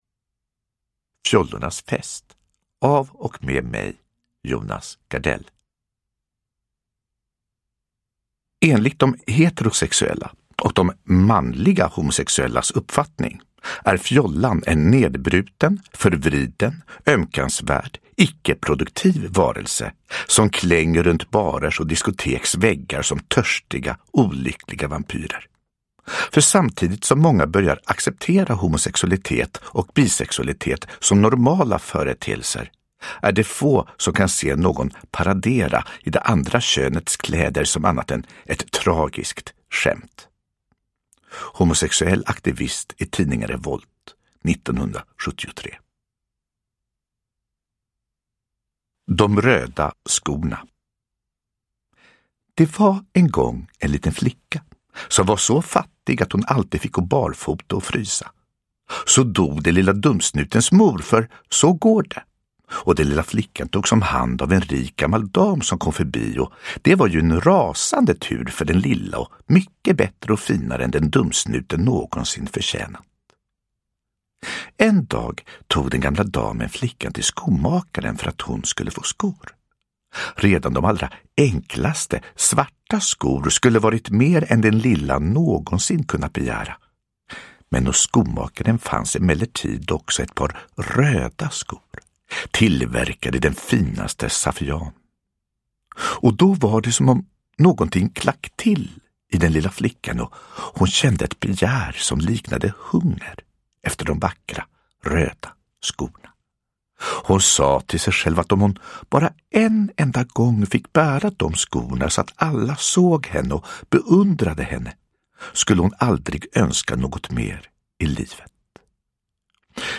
Fjollornas fest – Ljudbok – Laddas ner
Uppläsare: Jonas Gardell